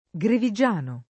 grevigiano [ g revi J# no ]